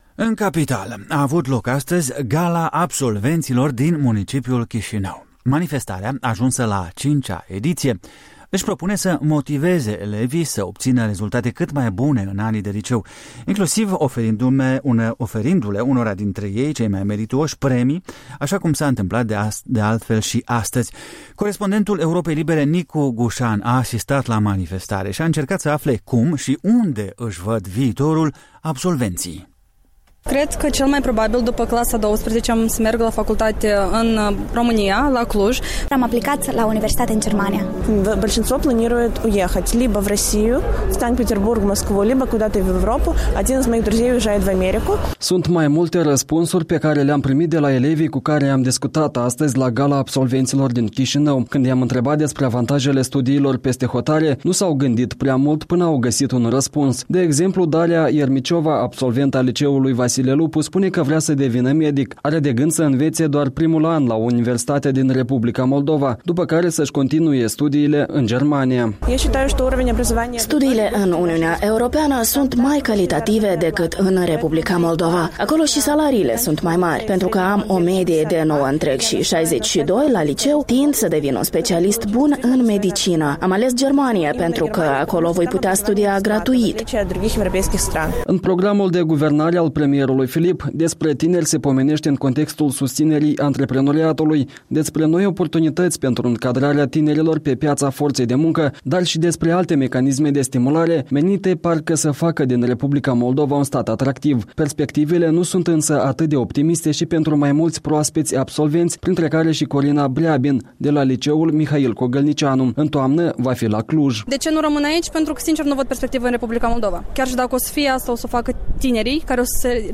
În direct de la Gala Absolvenților de la Chișinău